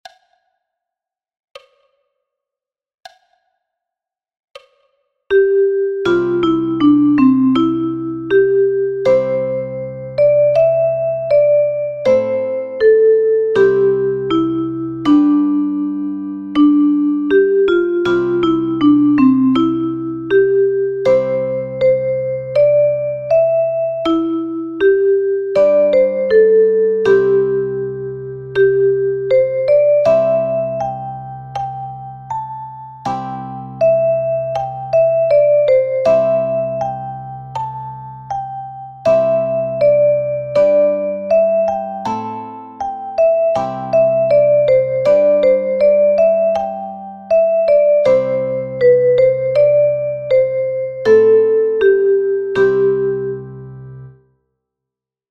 einfach notiert für die Paddy Richter Mundharmonika.